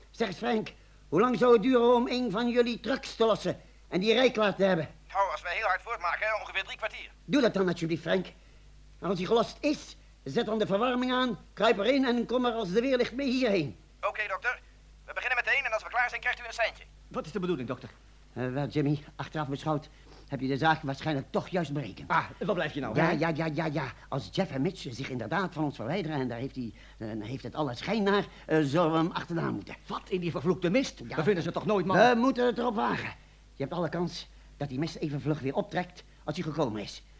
Hierin geeft Doc toe dat Jimmy/Lemmy waarschijnlijk toch een goede radiopeiling had gedaan op de verdwaalde mannen in de mist. Jimmy lijkt blijer met zijn gelijk dan Lemmy.